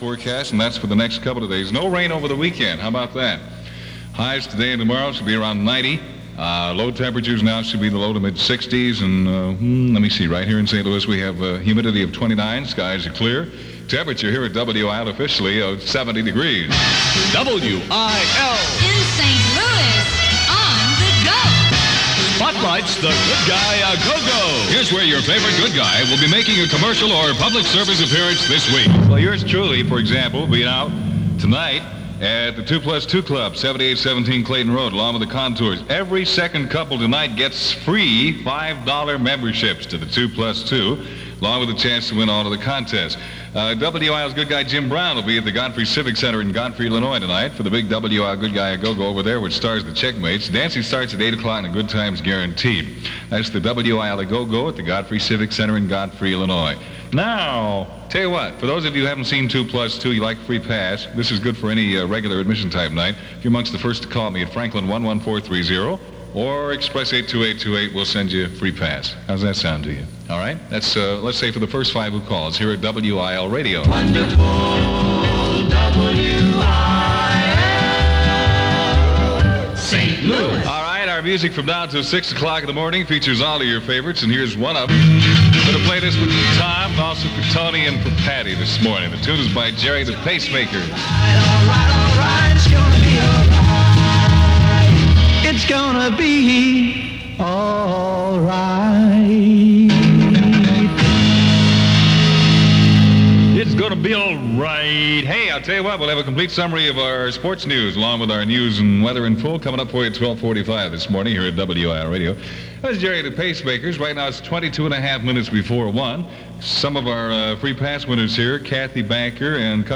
aircheck